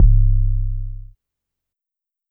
808 (Lightning Fire Magic Prayer).wav